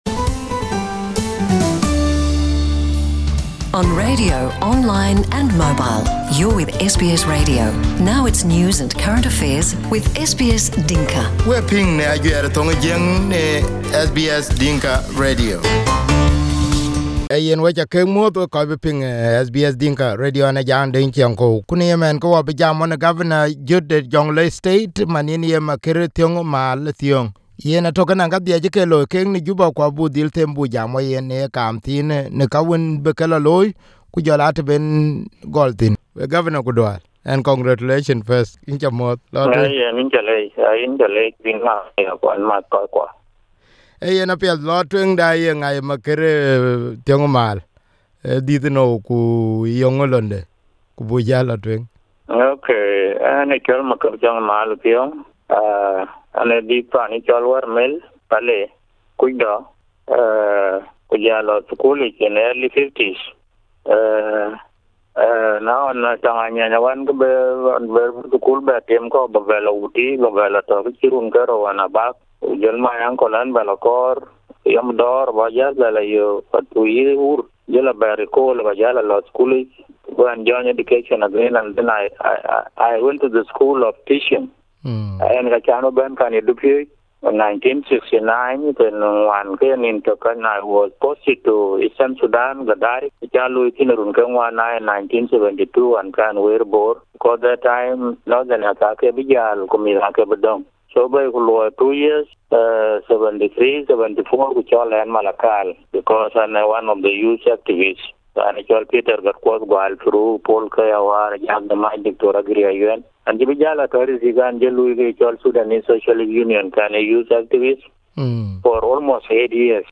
Jonglei State incoming Governor Maker Thiong Maal said that he will work with the citizens of Jonglei. Maker was speaking during his first interview on SBS Dinka since the time he was appointed by South Sudanese President.